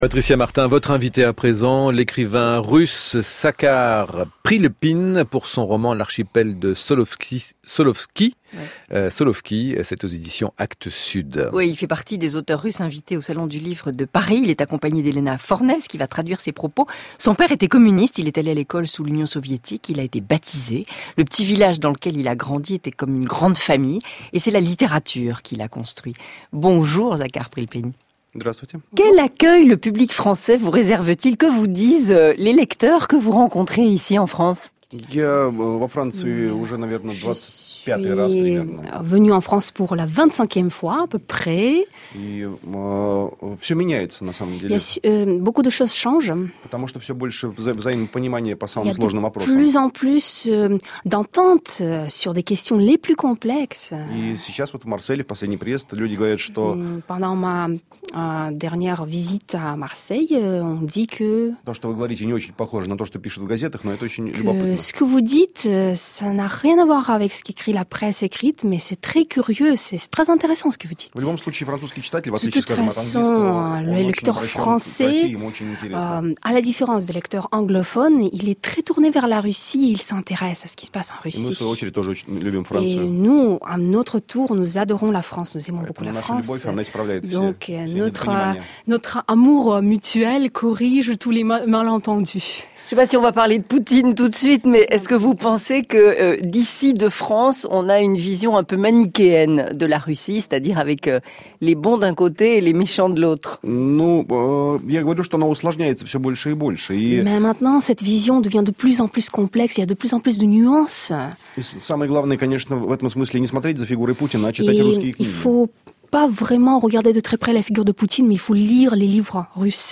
Interprétation simultanée
Le mode « voice over »
Dans ce cas de figure, l’interprétation couvre la voix de l’orateur, et le client entend directement l’interprétation.
Une de mes prestations en « voice over« , diffusée sur France Inter, ci-dessous.